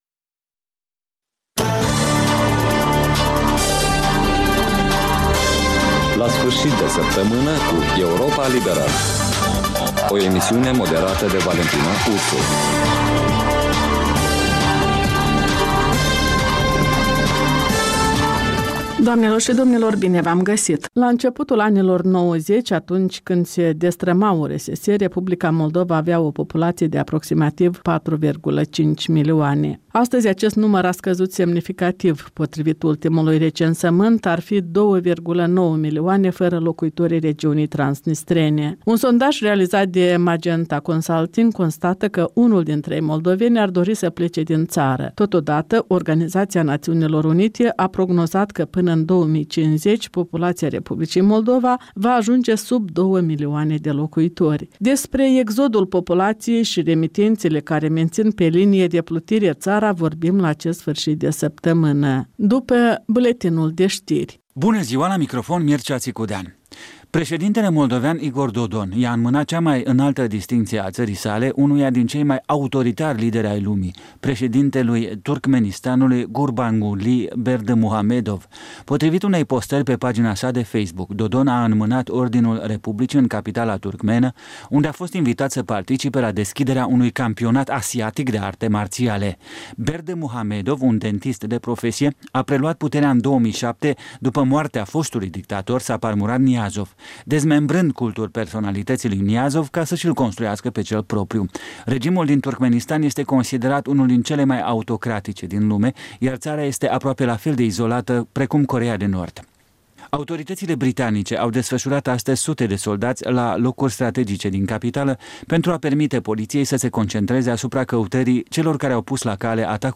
reportaje, interviuri, voci din ţară despre una din temele de actualitate ale săptămînii.